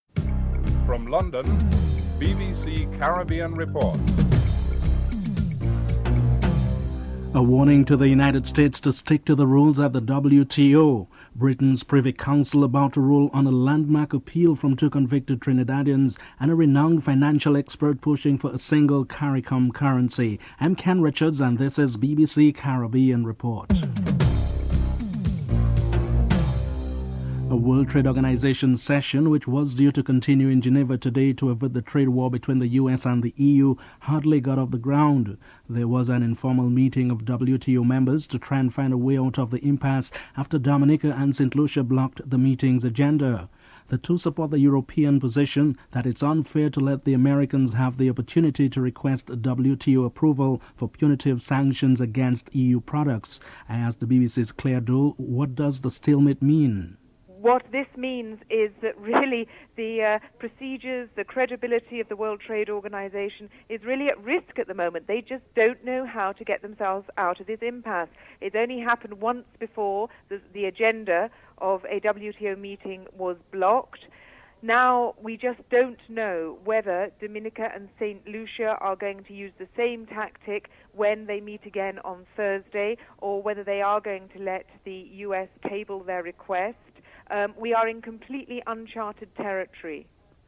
Jamaicans are interviewed on their views on hanging (11:31 - 12:31)